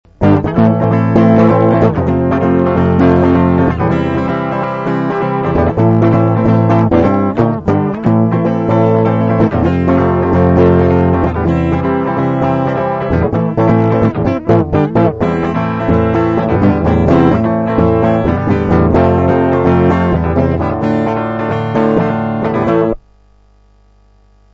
mp3 - припев